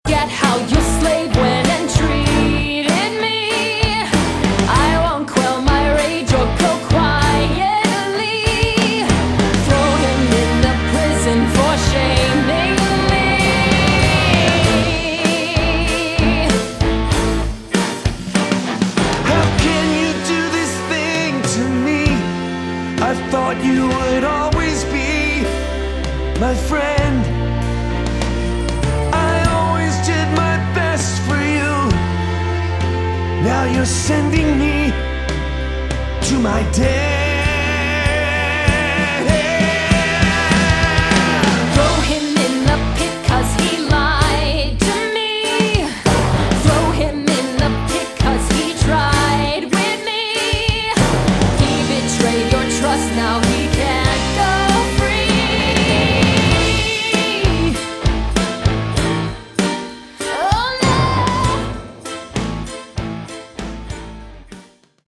Category: Prog Rock
guitars